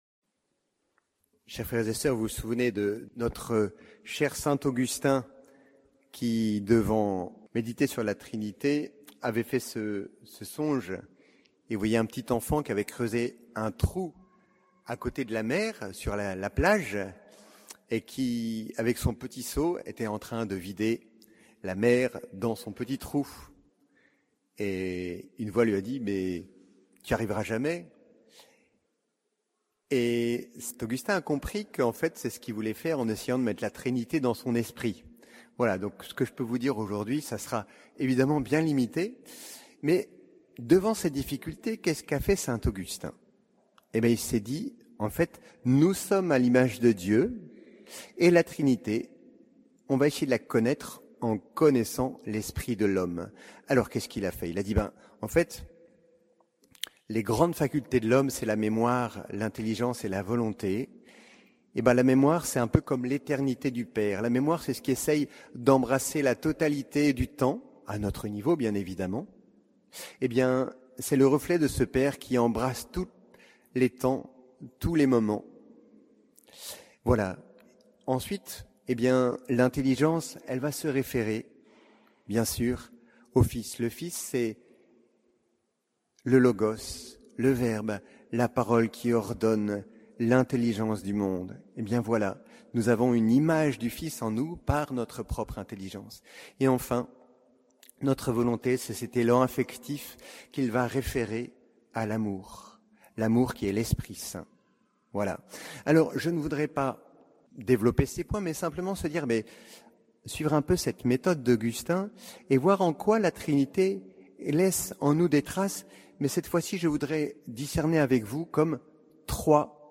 Homélie de la solenité de la Sainte Trinité